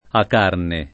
Acarne [ ak # rne ]